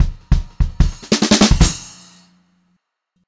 152H2FILL1-R.wav